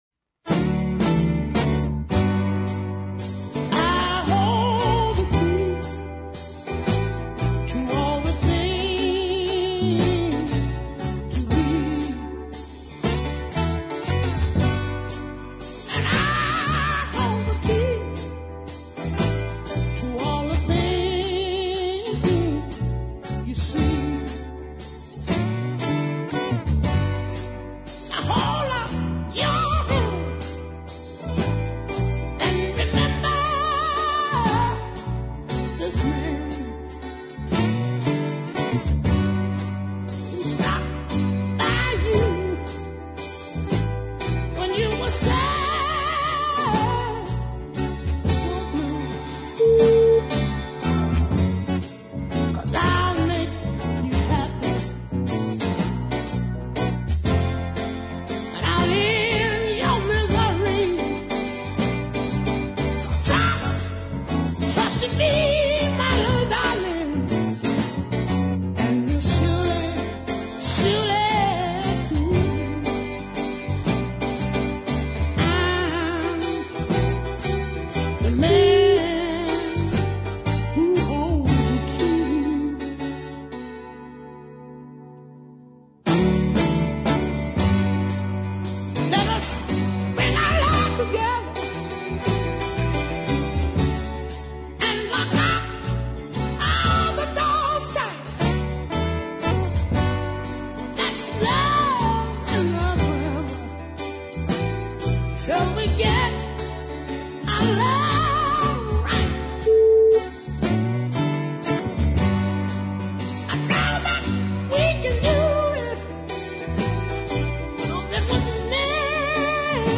Although these sides are clearly little more than demos
another fine throat tearing vocal performance